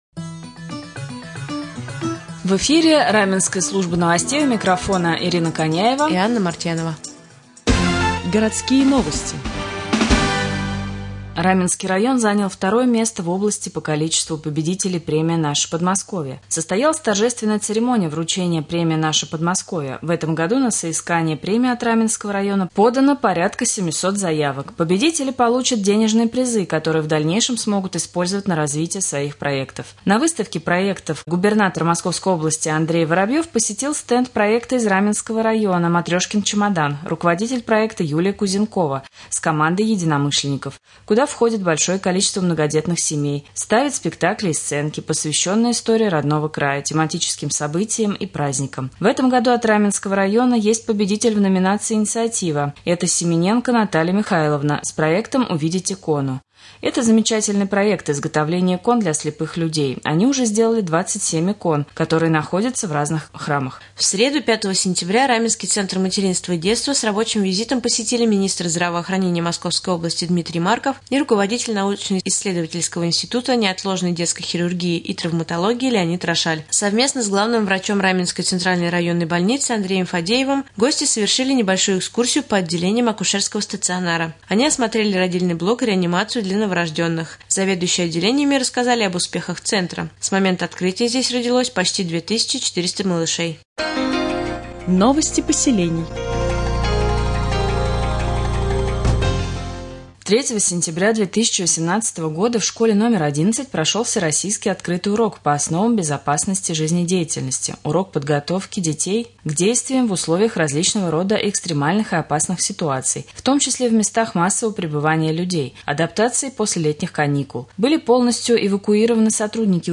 3.Новости Подмосковья и Раменского района